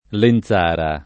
[ len Z# ra ]